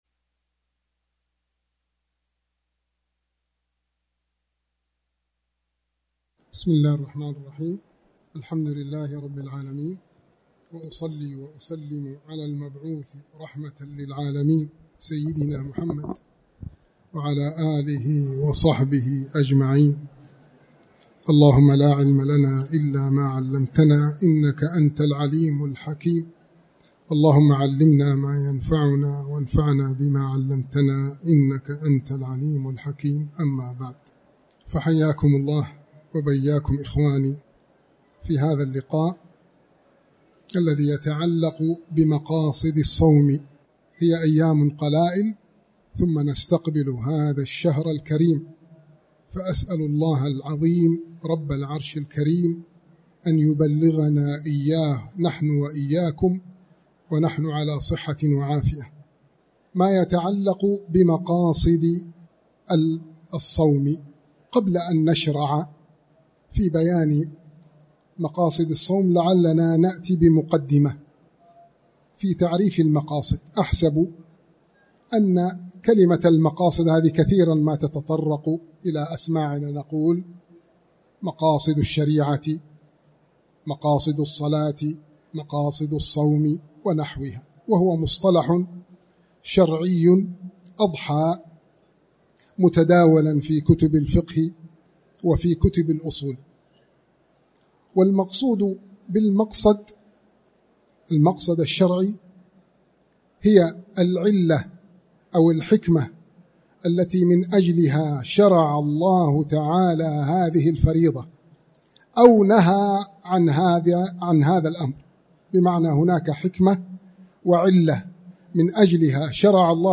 23شعبان-محاضرة-مايتعلق-بمقاصد-الصوم-1.mp3